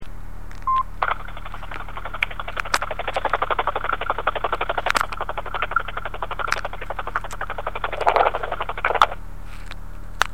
ok, I just test drove them again...after driving for 8 miles and on my way home I was about to park my car and all of sudden car seemed to have misfiring again. but idle was really normal, it wasn't moving but noise was constant. I didn't hear any loud knocking noises from engine compartment either but exhuast was making constant misfiring noises.
sound wasn't that great so I went out there to record noise again but car seems to be fine..again..wtf?